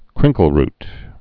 (krĭngkəl-rt, -rt)